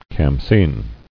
[kham·sin]